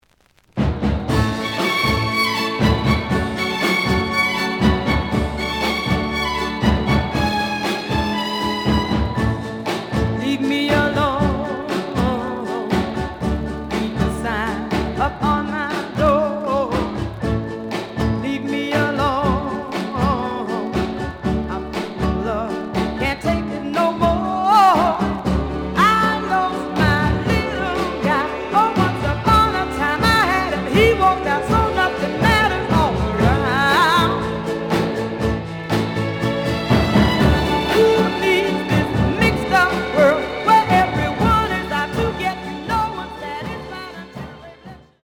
The audio sample is recorded from the actual item.
●Genre: Rhythm And Blues / Rock 'n' Roll
Some damage on both side labels. Plays good.)